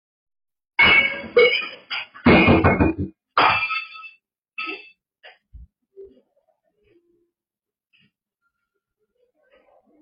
Out of nowhere, the kitchen erupted in chaos. Glasses toppled, plates smashed, and shards of glass scattered across the floor as if hurled by invisible hands.
Yet the noise grew, deliberate and unsettling, as though something wanted to be noticed.